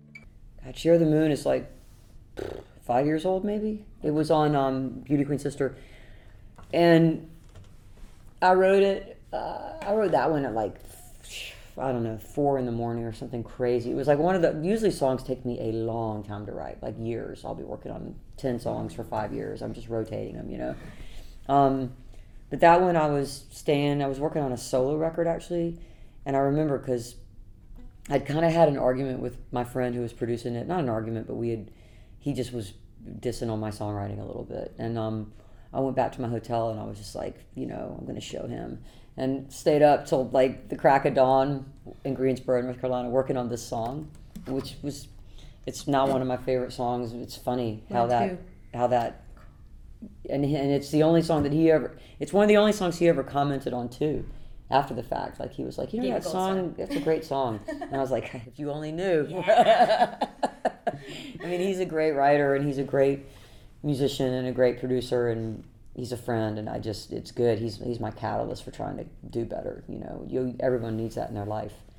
04. interview (1:12)